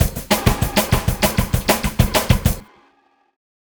Swinging 60s Drumz Dryer.wav